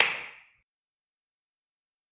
Звуки Дай пять: Звук пятюни